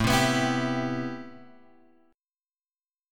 A Minor 9th